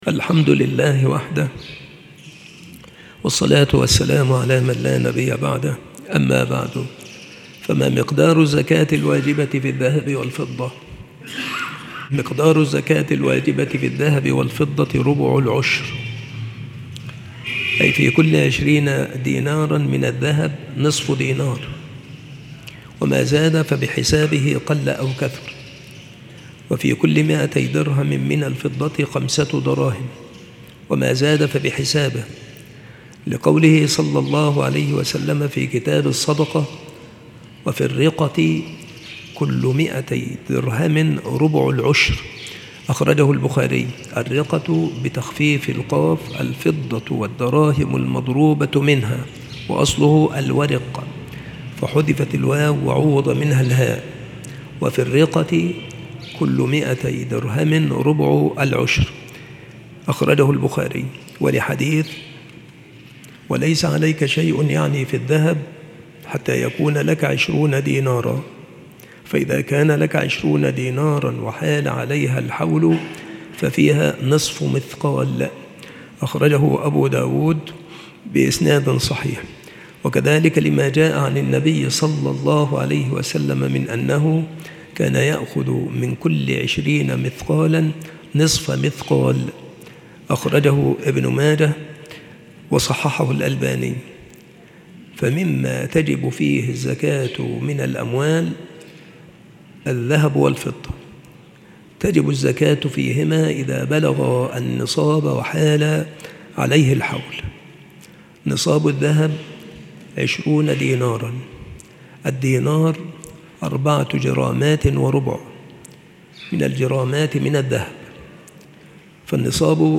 مكان إلقاء هذه المحاضرة بالمسجد الشرقي - سبك الأحد - أشمون - محافظة المنوفية - مصر